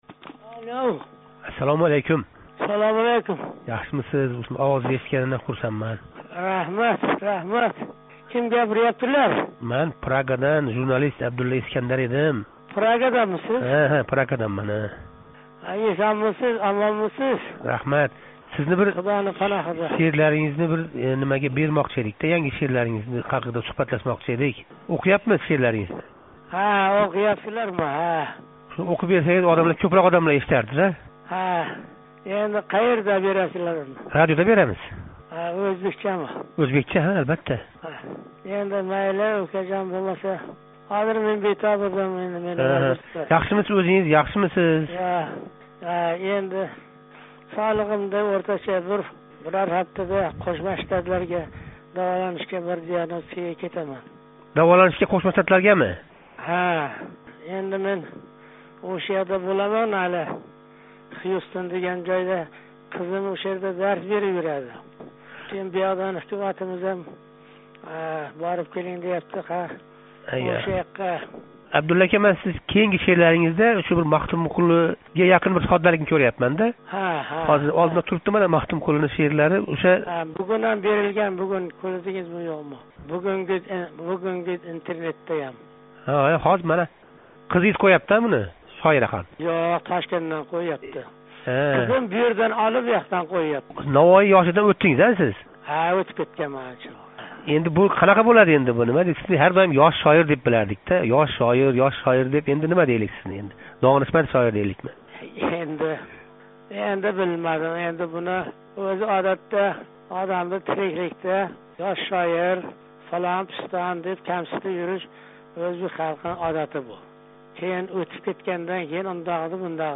Ўзбек шеъриятида Навоийдан кейин энг кўп мутолаа қилинган шоир, деб билинган 75 яшар Абдулла Орипов Озодликка берган эксклюзив интервьюсида ўзбек миллатининг бугунги ҳолидан ўкинишини таъкидлади.